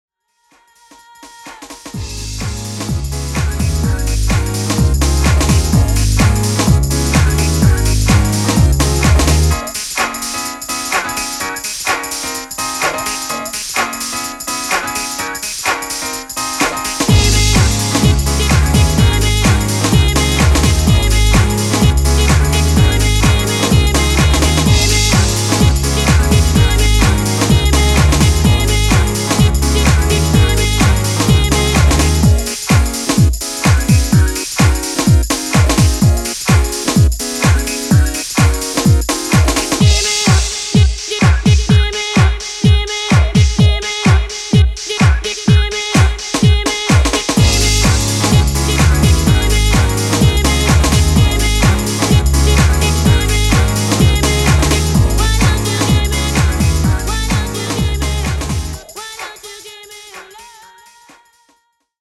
アップリフトな